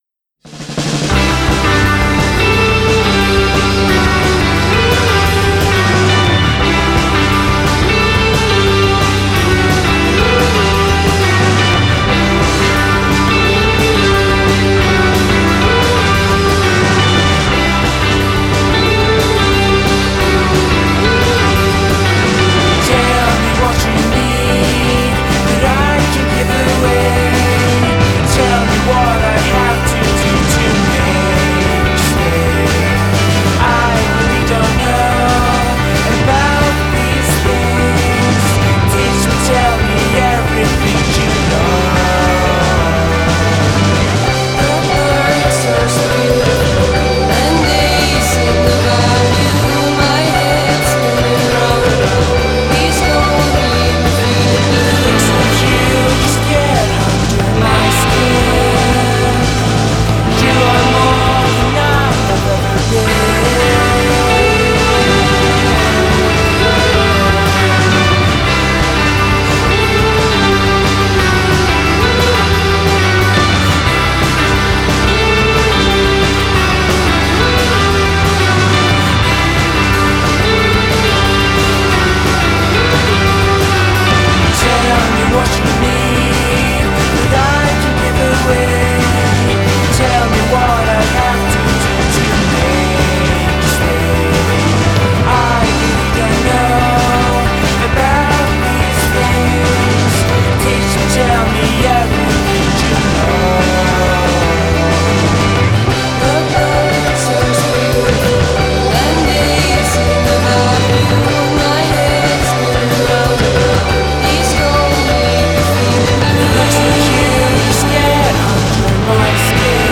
un formidabile collisione tra rumore, velocità e melodia
è uno dei migliori dischi indiepop dell'anno.